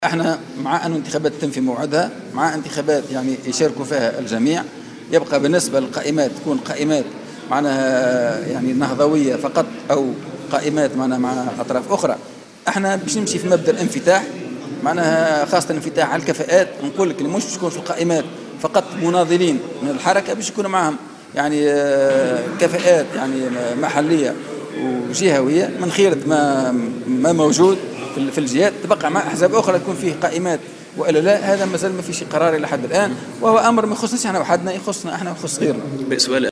وجاء تصريحه على هامش موكب تسليم القيادة المحلية للمنتخبين الجدد بالحركة في سوسة، أكد فيه تأييد الحركة على اجراء الانتخابات البلدية في موعدها.